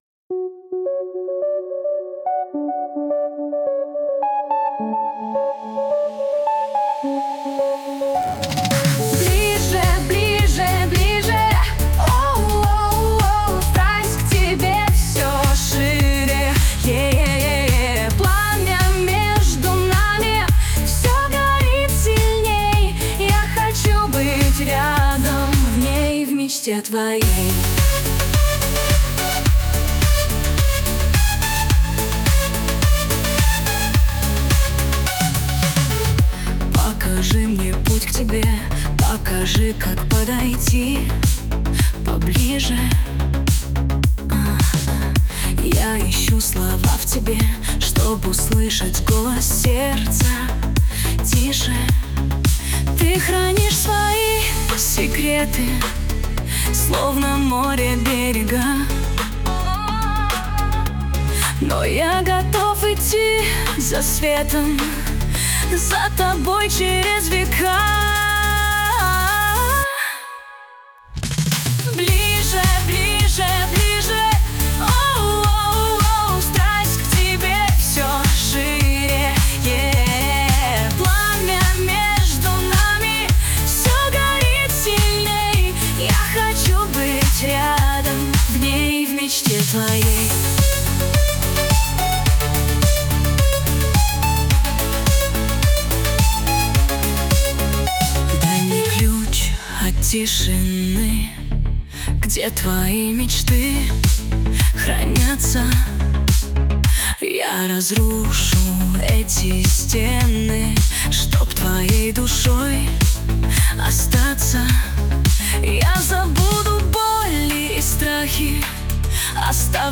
RUS, Romantic, Dance, Pop, Disco | 16.03.2025 10:43